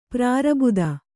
♪ prābuda